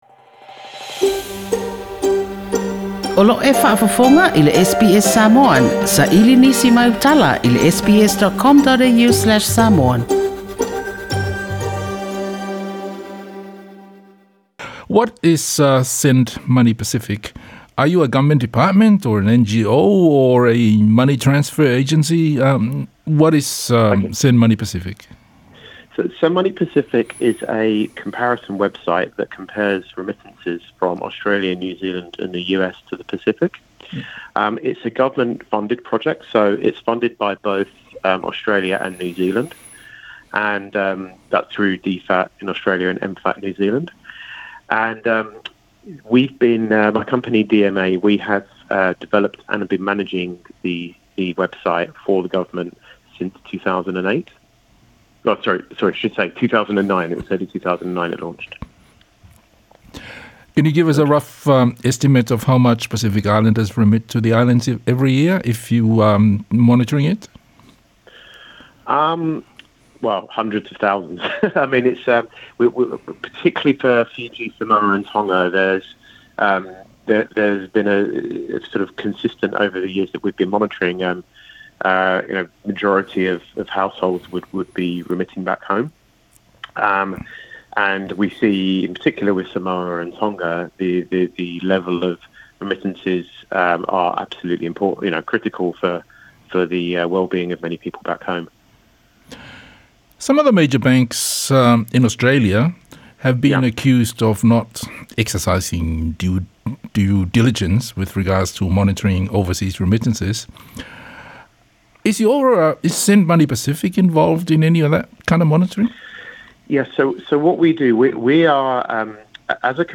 Se talanoaga